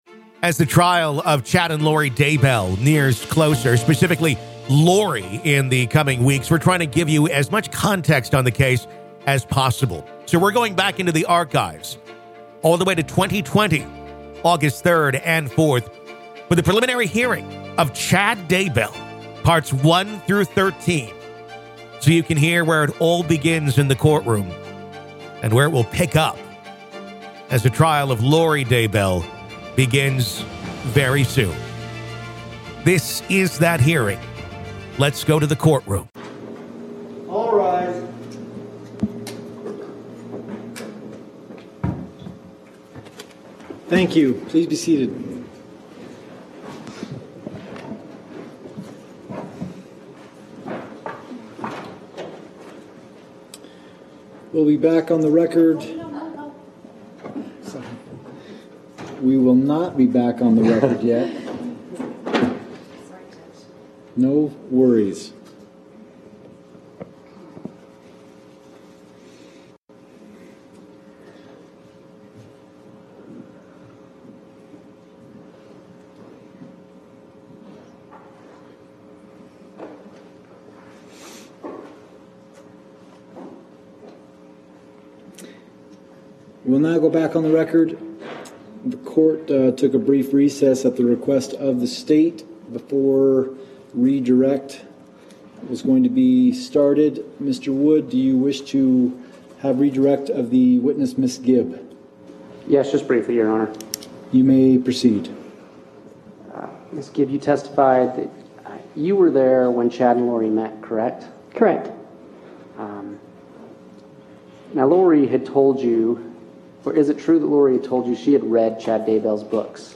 Listen To The Full Preliminary Hearing Of Chad Daybell, Part 10